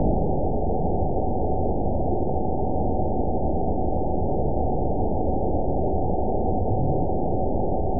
event 921785 date 12/19/24 time 01:37:01 GMT (4 months, 1 week ago) score 7.85 location TSS-AB01 detected by nrw target species NRW annotations +NRW Spectrogram: Frequency (kHz) vs. Time (s) audio not available .wav